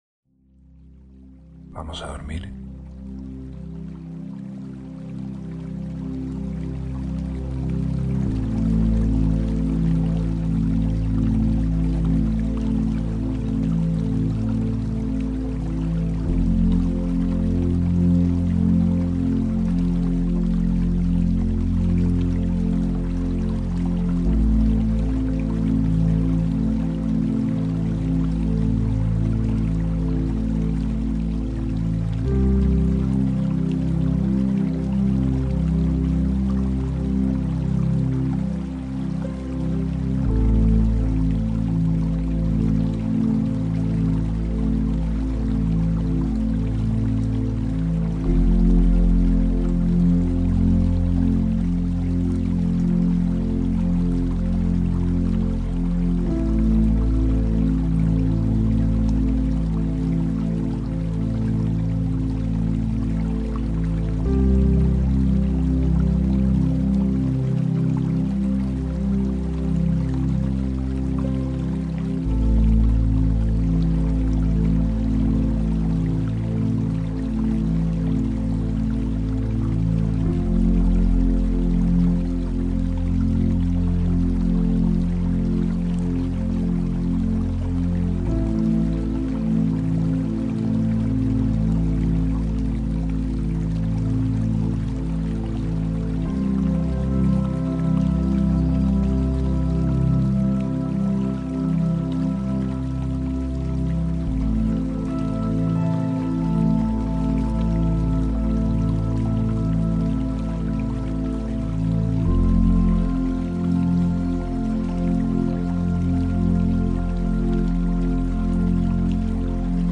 Ambientes para Dormir ✨ Bosque con animales
Experiencias inmersivas para ayudarte a dormir profundamente.